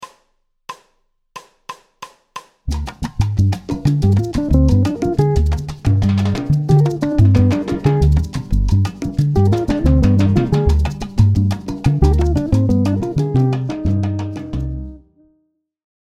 Phrases – cadence ii Ø . V7±9 en mineur
Phrase 02 : La phrase s’appuie sur la gamme de C mineur harmonique et l’accord de Septième emploie l’arpège de B diminué {B D F Ab} qui est construit à partir du Septième degré de cette gamme.